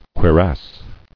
[cui·rass]